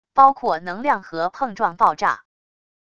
包括能量盒碰撞爆炸wav音频